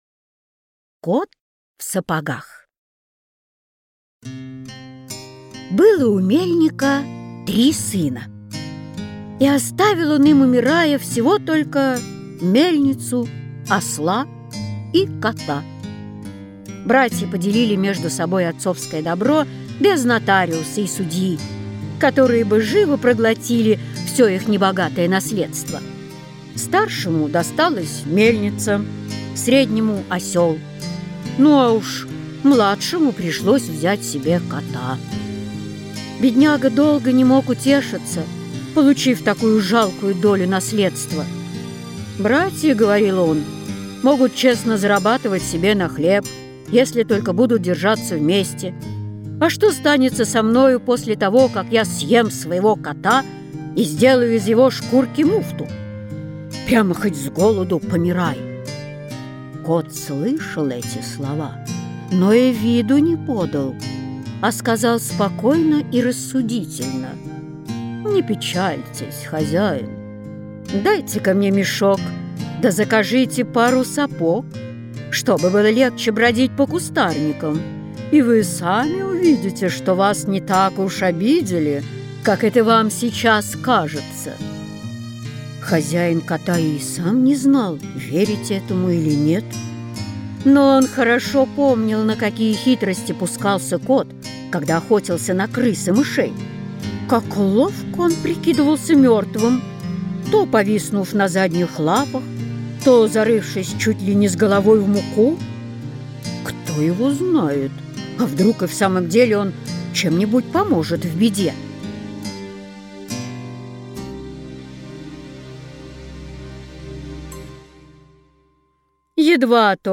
Аудиокнига Сказки Шарля Перро | Библиотека аудиокниг